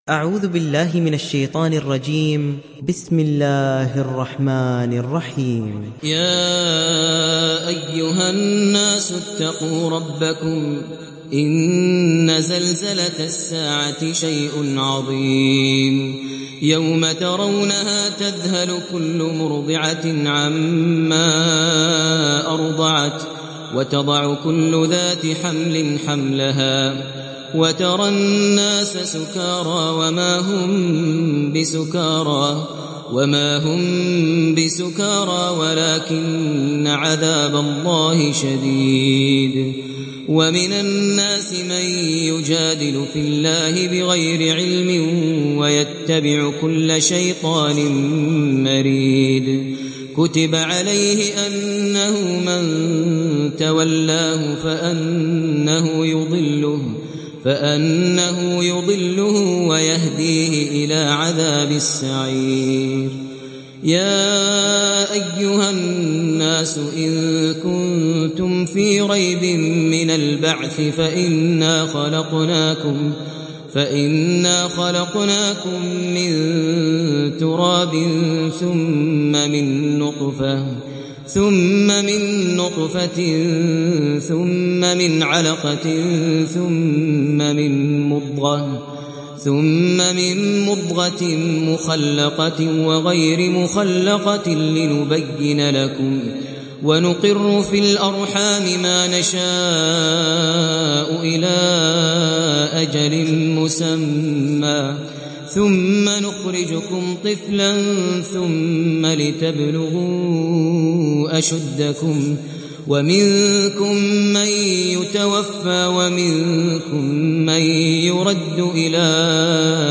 Surat Al Haj mp3 Download Maher Al Muaiqly (Riwayat Hafs)